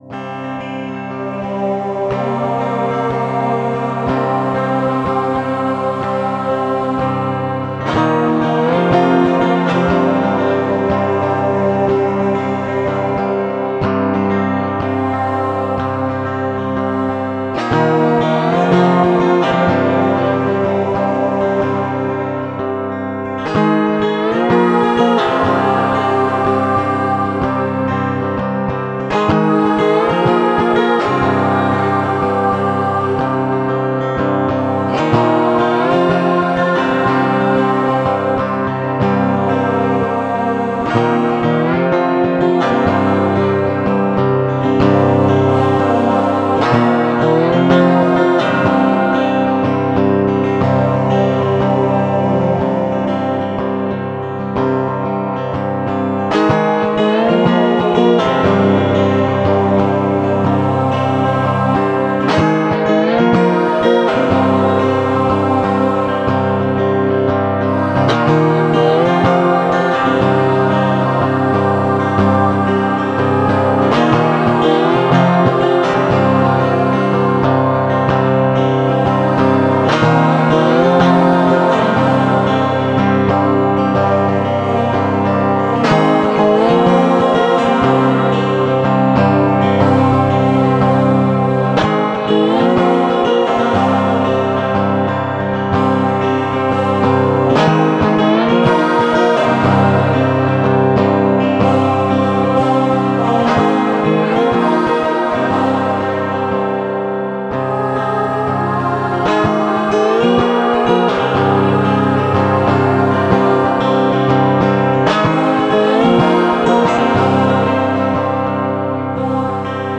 Epic Folk Gitarre mit Chor.